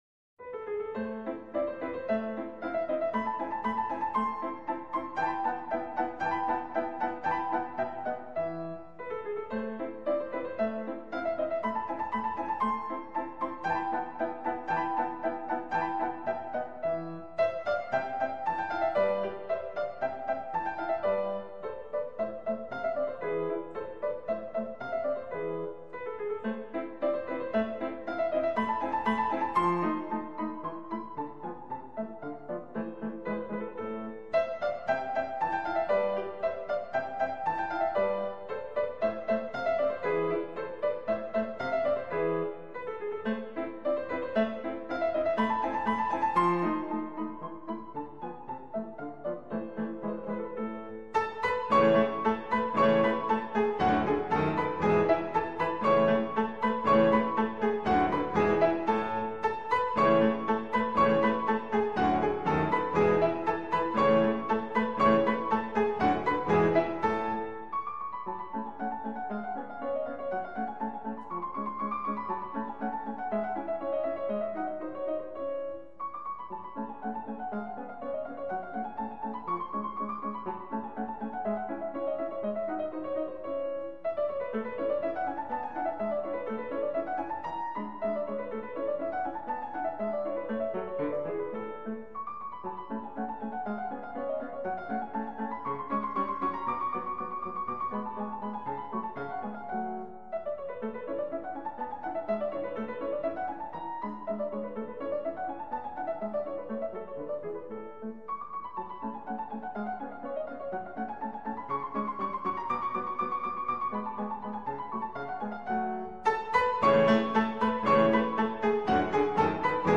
内  容： 世界古典名曲 Highlights